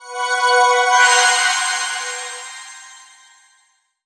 twinkle_glitter_dark_spell_03.wav